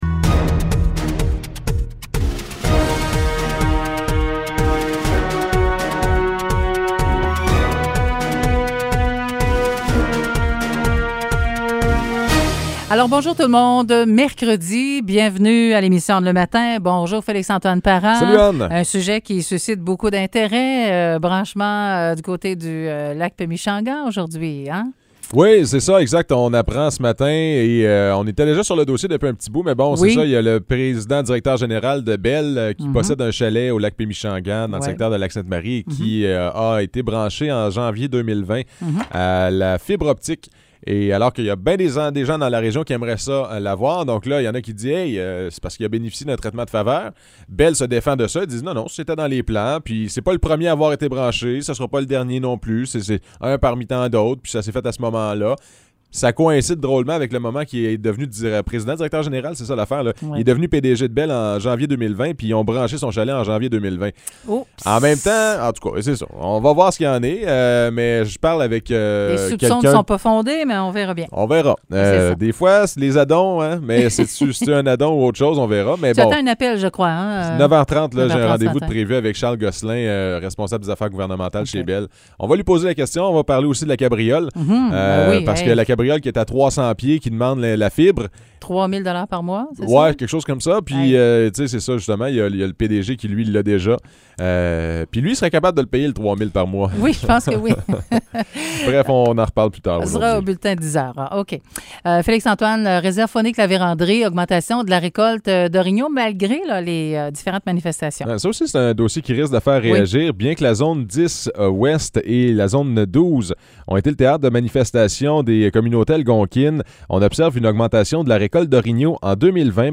Nouvelles locales - 24 février 2021 - 9 h